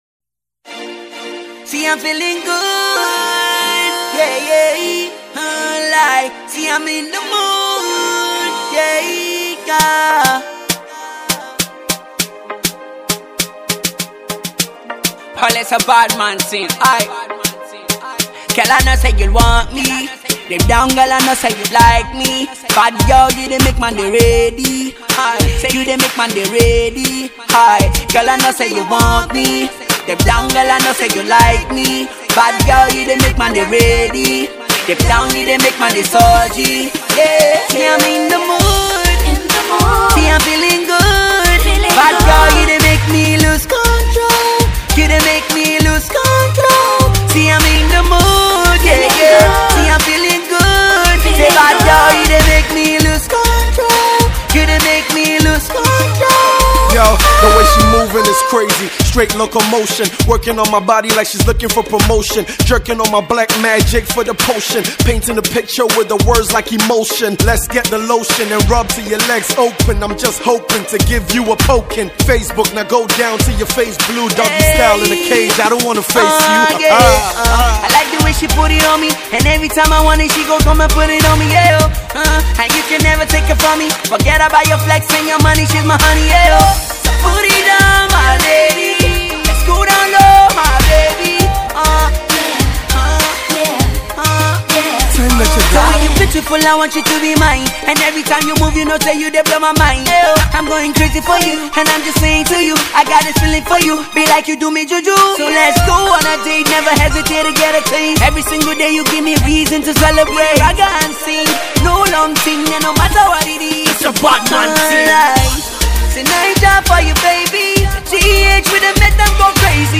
Naija Crooner
is a remix of the Jamaican